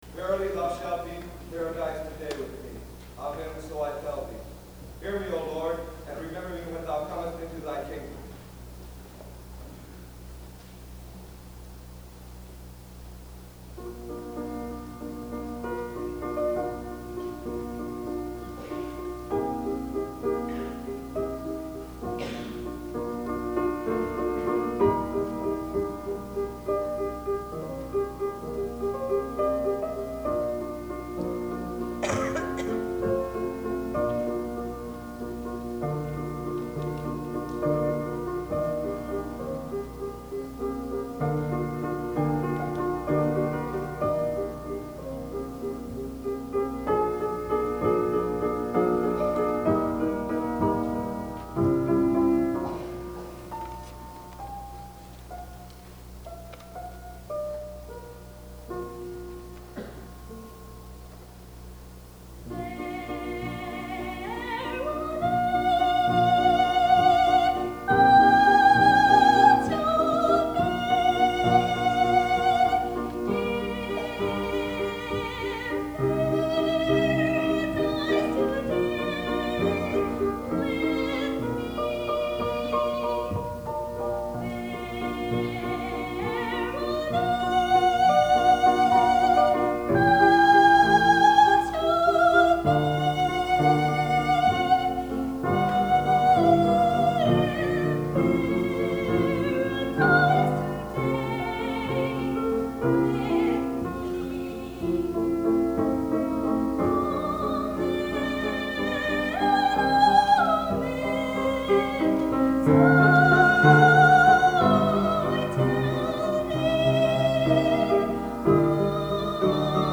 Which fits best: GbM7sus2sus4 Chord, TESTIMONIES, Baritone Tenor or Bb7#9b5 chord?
Baritone Tenor